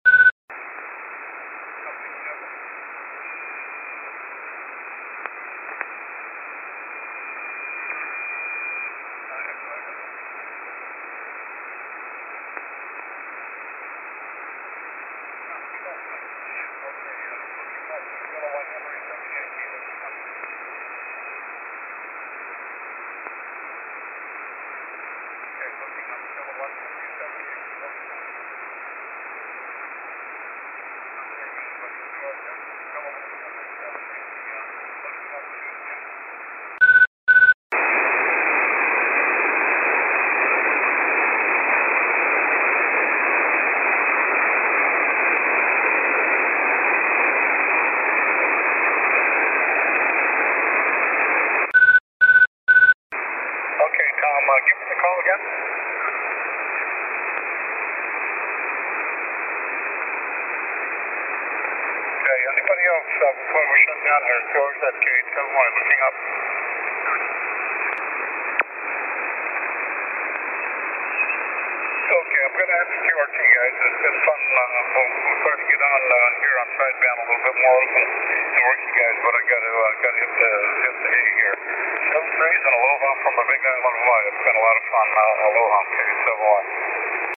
I have marked the start of the inverted L with one beep, the dipole with two beeps, and the Steppir with three.
Faint on the inverted L, no copy on the dipole.